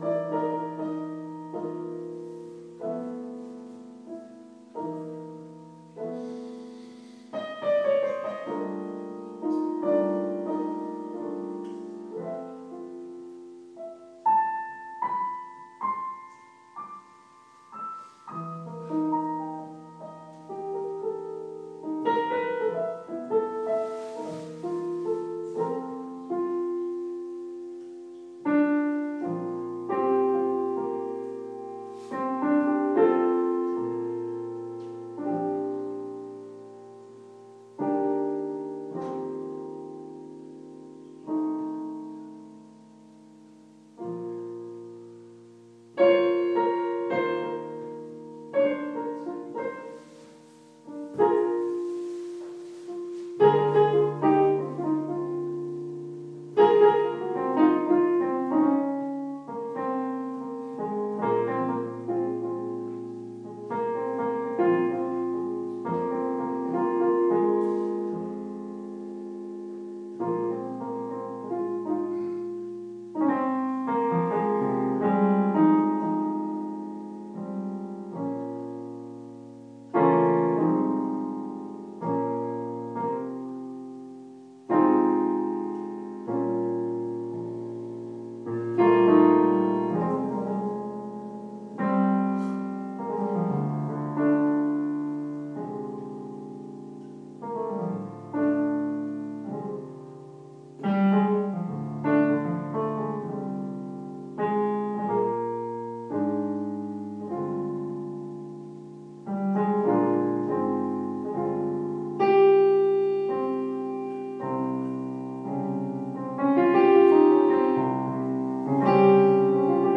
Brotfabrik Berlin, Galerie